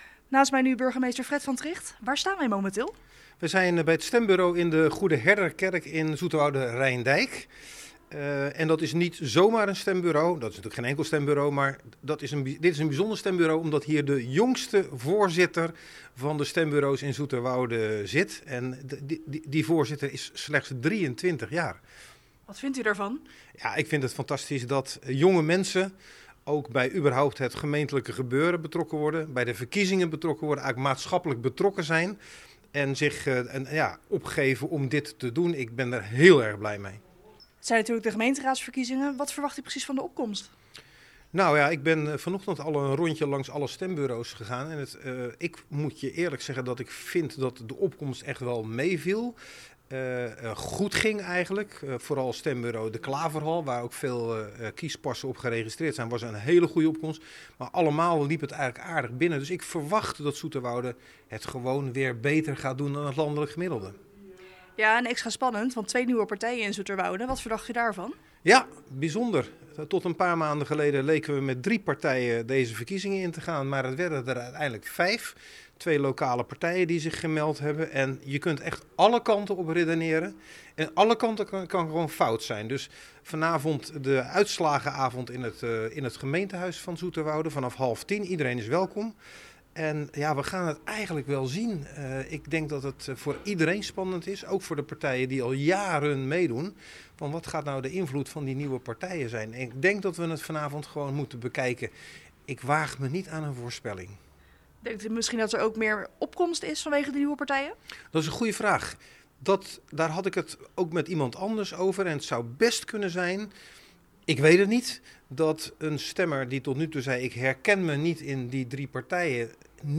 in gesprek met burgemeester Fred van Trigt.